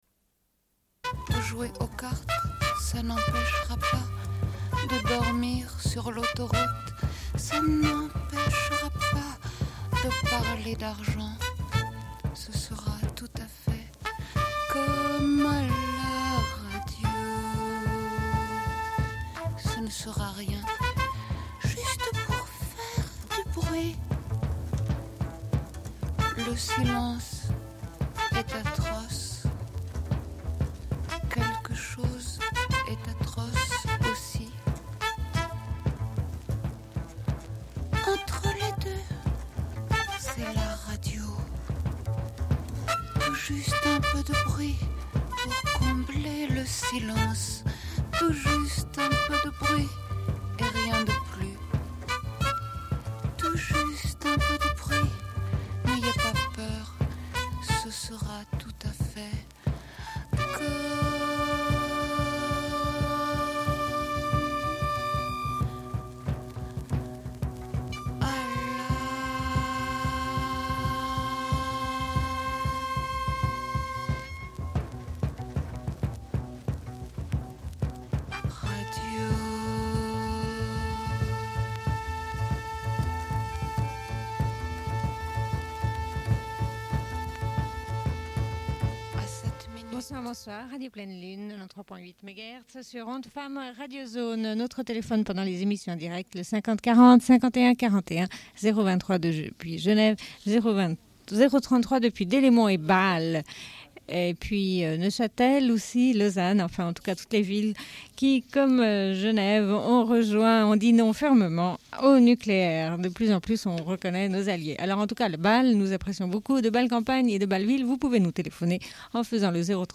Bulletin d'information de Radio Pleine Lune.